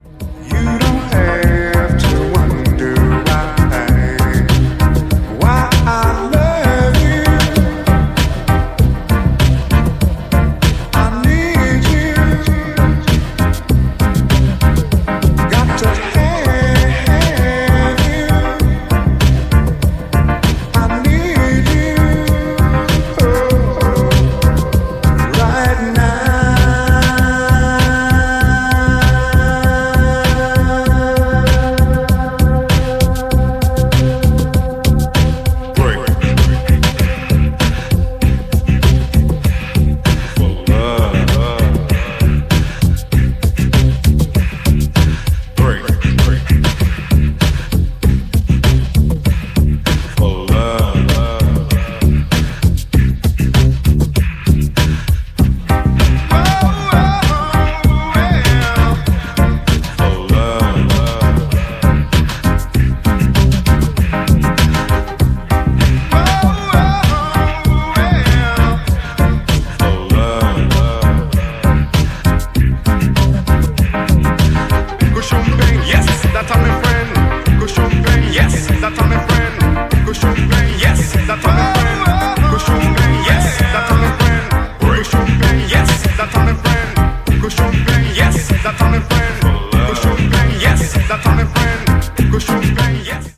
ジャンル(スタイル) DISCO / NU DISCO / BALEARIC / EDITS